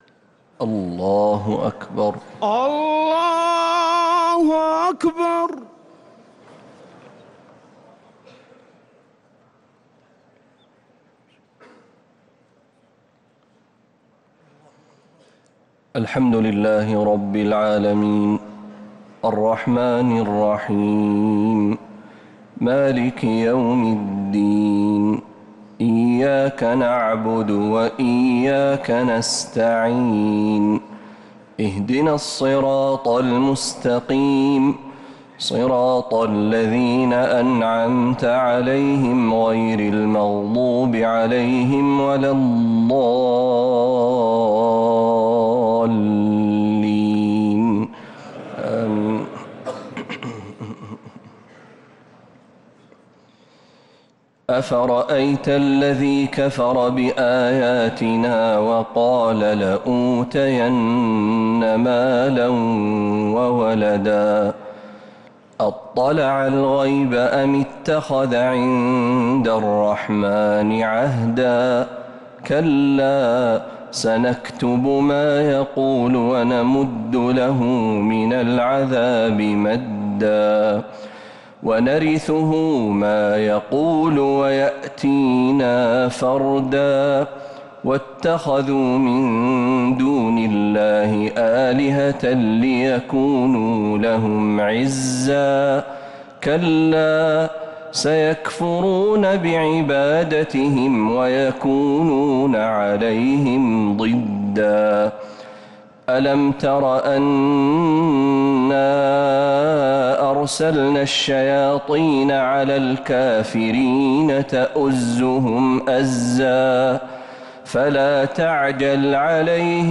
تراويح ليلة 21 رمضان 1447هـ من سورتي مريم (77-98) و طه (1-76) | Taraweeh 21st night Ramadan 1447H Surah Maryam and TaHa > تراويح الحرم النبوي عام 1447 🕌 > التراويح - تلاوات الحرمين